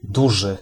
Ääntäminen
IPA : /ˈbɪɡ/